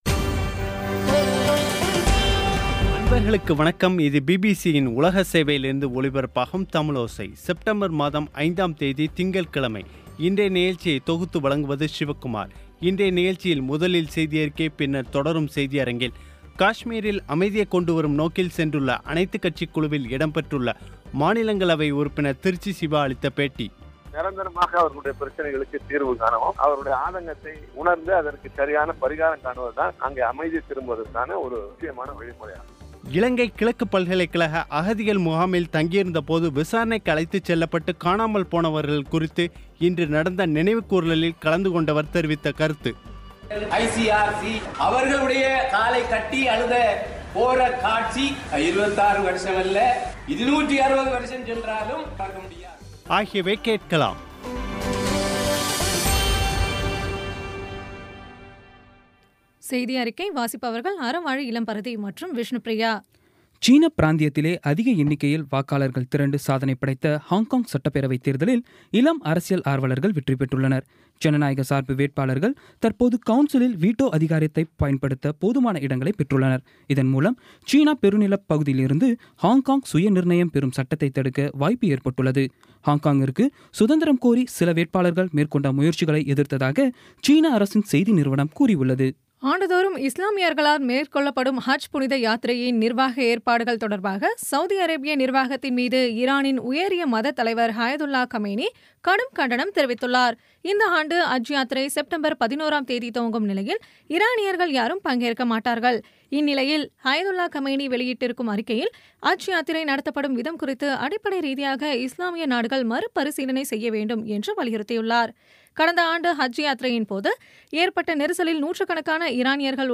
இன்றைய நிகழ்ச்சியில் முதலில் செய்தியறிக்கை, பின்னர் தொடரும் செய்தியரங்கில்
இந்திய நிர்வாகத்துக்குட்பட்ட காஷ்மீரில், அமைதியை கொண்டு வரும் நோக்கில் சென்றுள்ள அனைத்துக் கட்சி குழுவில் இடம்பெற்றுள்ள மாநிலங்களவை உறுப்பினர் திருச்சி சிவா அளித்த பேட்டி